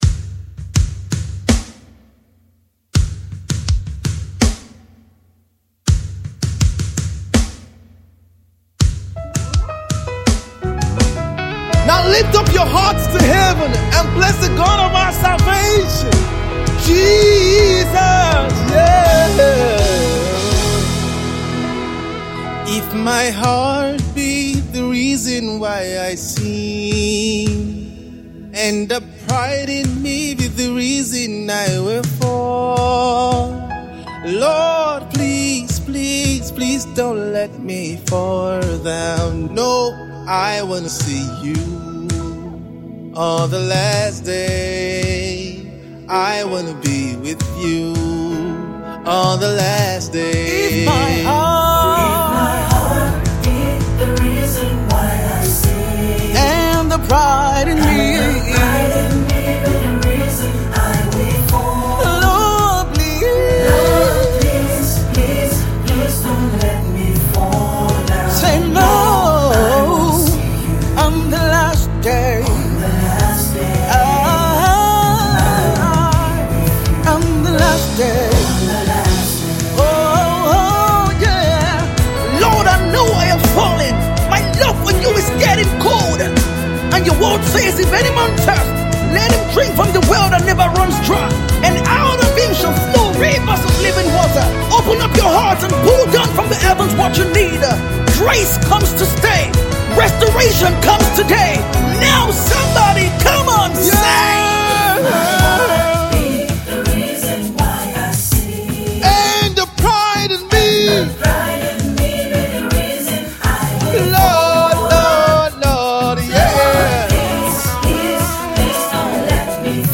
song of worship